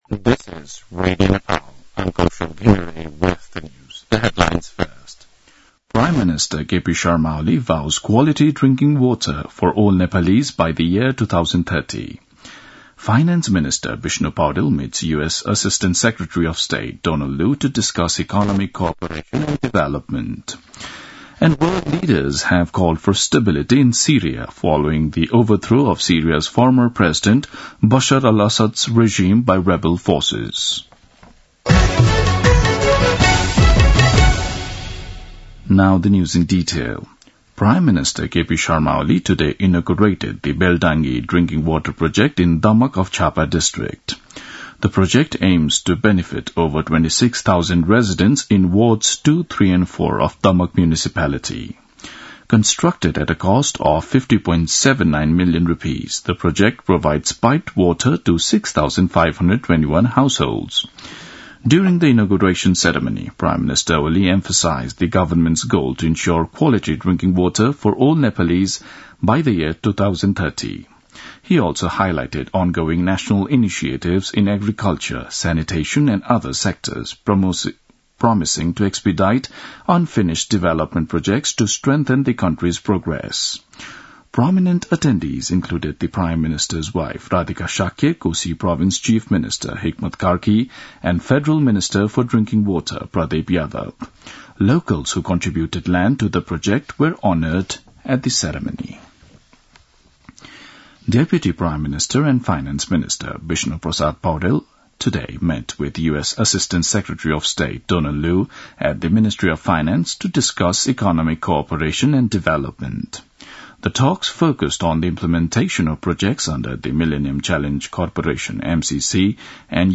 दिउँसो २ बजेको अङ्ग्रेजी समाचार : २५ मंसिर , २०८१
2-pm-english-news-1-7.mp3